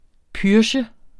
Udtale [ ˈpyɐ̯ɕə ]